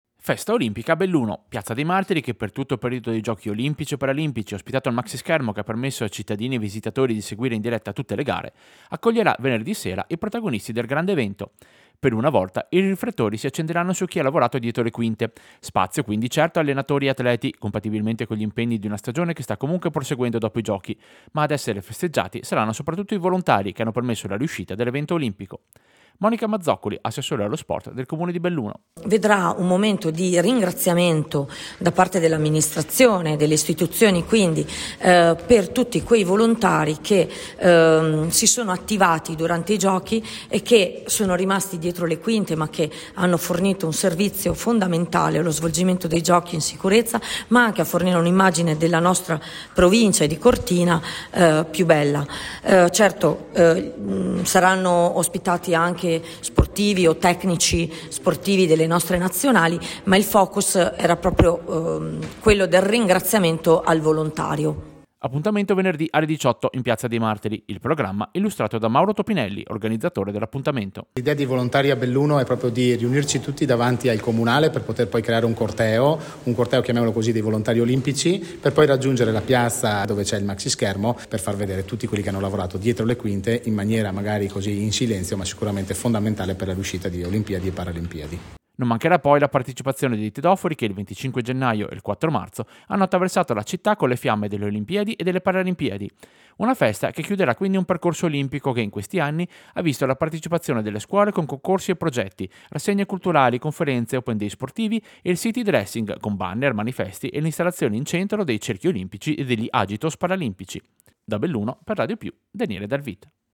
Servizio-Festa-volontari-olimpici-Belluno.mp3